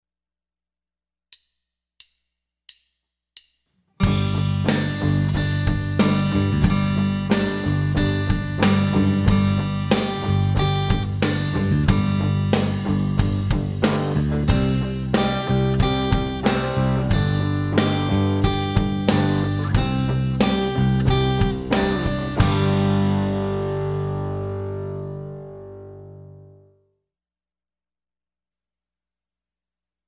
Rock Freebies